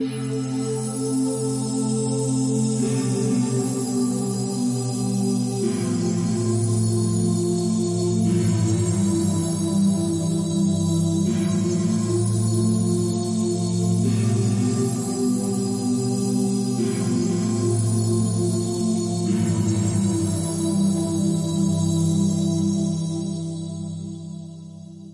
现场太空垫06
描述：活Krystal Cosmic Pads